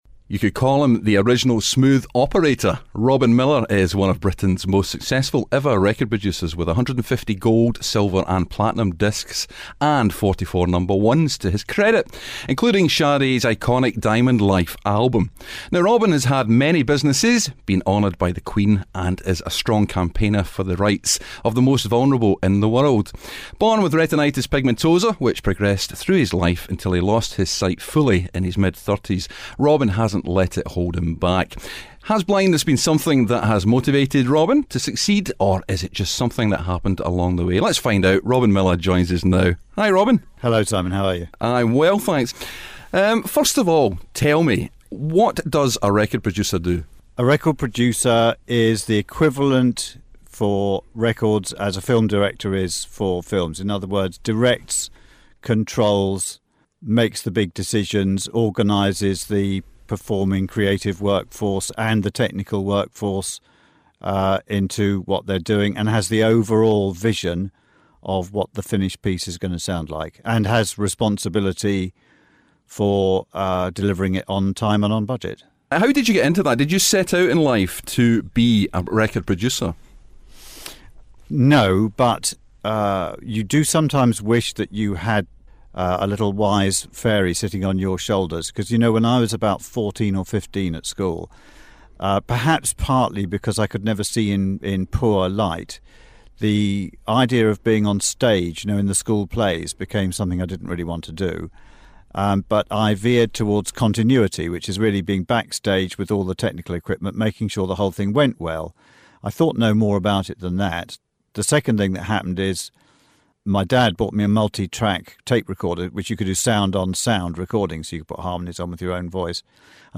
Legendary producer & businessman Robin Millar, CBE talks about his illustrious career & living with the sight condition retinitis pigmentosa.